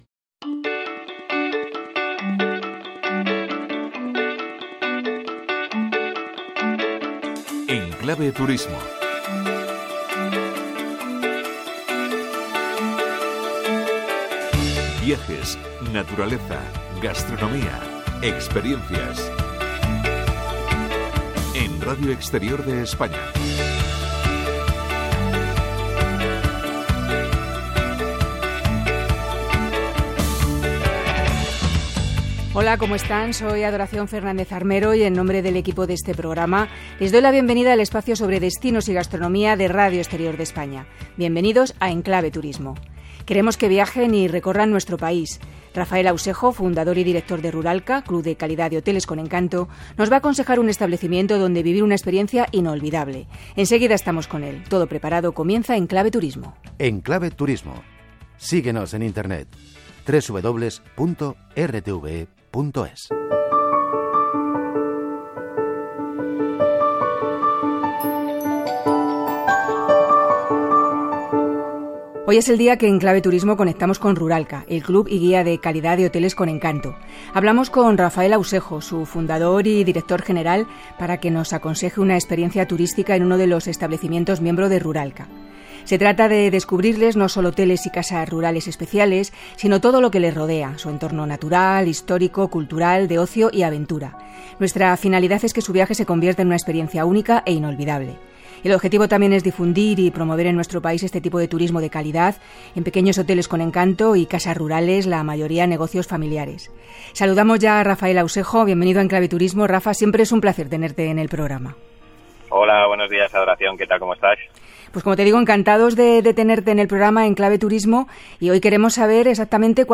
Careta del programa, presentació, indicatiu
Entrevista